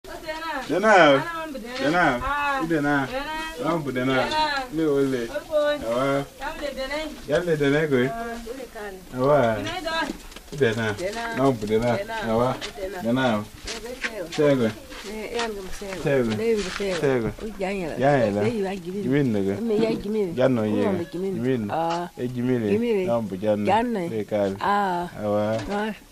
In fact, when it comes to saluting our fellows, we could probably all learn something from the Dogon people in the West African country of Mali, where I recently spent a couple of months.
Unlike us, they have elaborate greetings.
regular-greeting.mp3